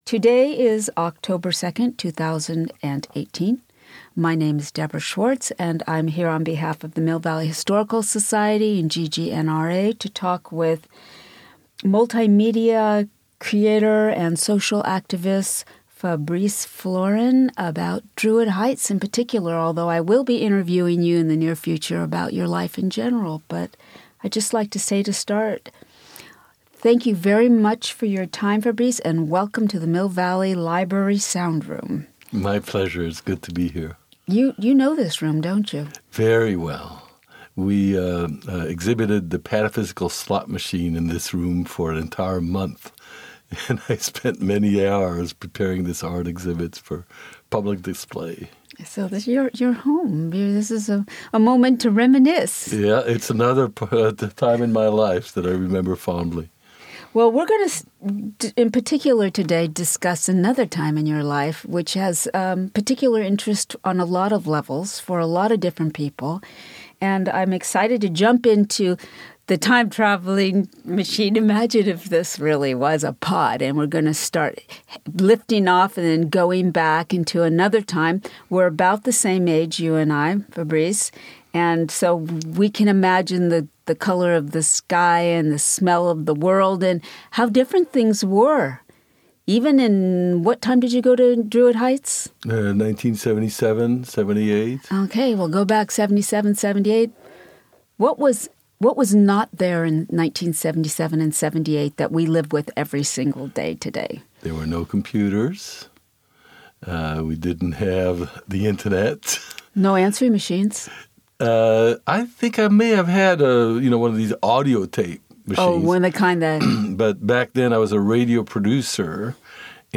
Druid Heights Oral History